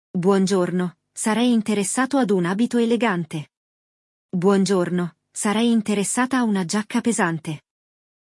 No episódio de hoje, vamos ouvir um diálogo entre uma vendedora e um senhor que quer comprar um presente para a sua esposa, para o aniversário de casamento deles.
Sim, o diálogo é simples e acompanhado de explicações para facilitar o aprendizado.